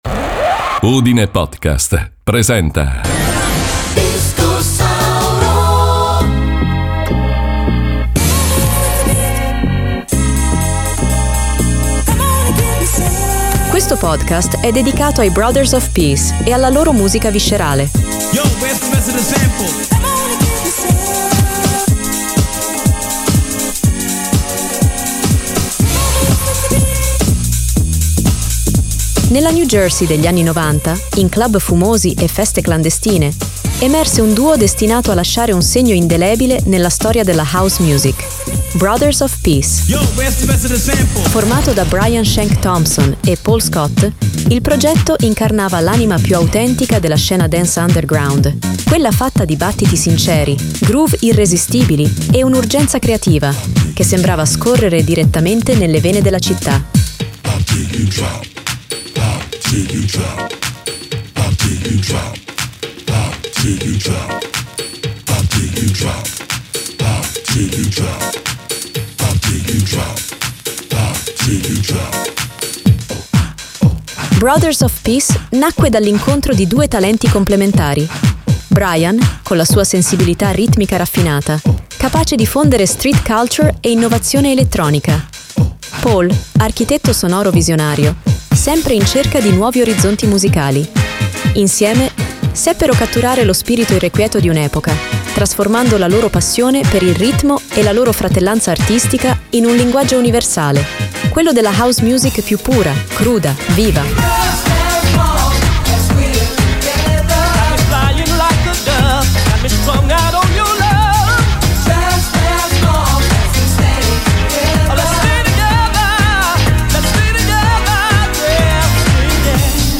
Il loro suono era un’esplosione controllata di energia: bassline rotonde, percussioni serrate, melodie ipnotiche e una forza emotiva che travolgeva chiunque li ascoltasse.